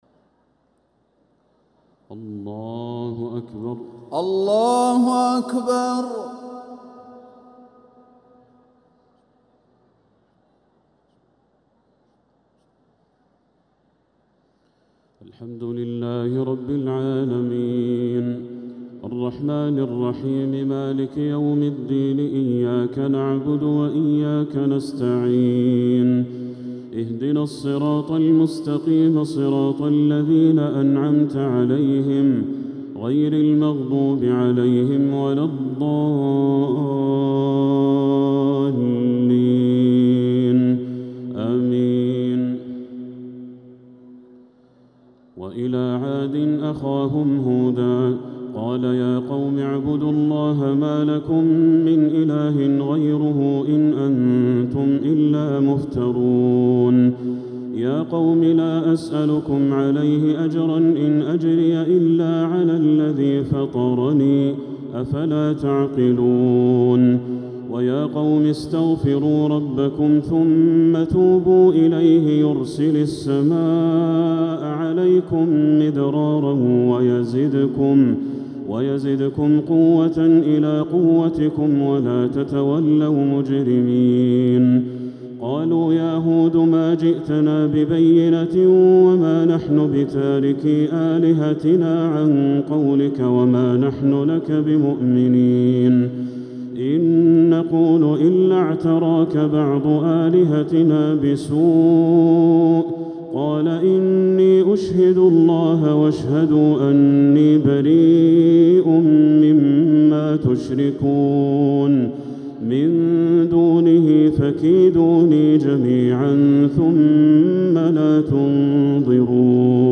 تراويح ليلة 16 رمضان 1447 من سورة هود (50-109) | Taraweeh 16th Ramadan 1447H Surat Hud > تراويح الحرم المكي عام 1447 🕋 > التراويح - تلاوات الحرمين